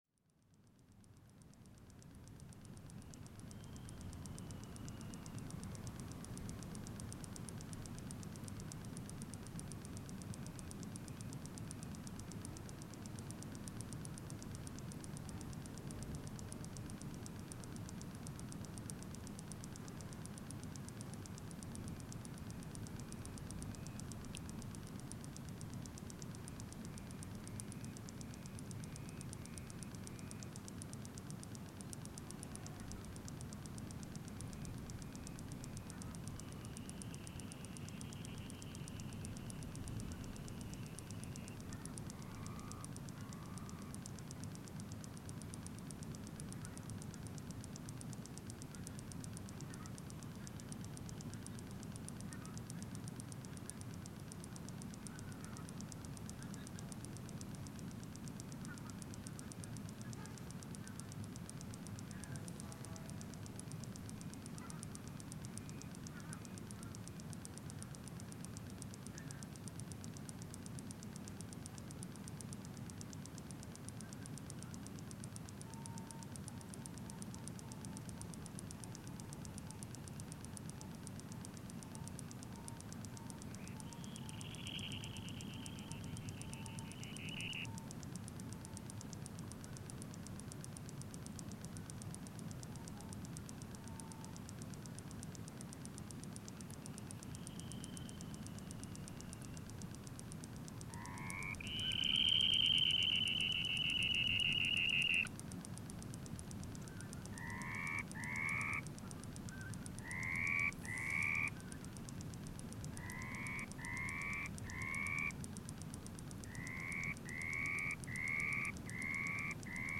Howling fox
Rode NT1a, Sound Devices 744
I just like to say, it was recorded in the highland in one of my „hidden places“ that still exists and has not been destroyed with hydro power plant, tourists, offroad driving motorist, or human waste. This recording is very quiet. It contains a dunlin, howling arctic fox, europian golden plover, red necked phalarope and pink footed goose. The background noise is a waterflow from nearby water source.
Quality open headphones are recommended while listening at low to mid level, or in speakers at low level .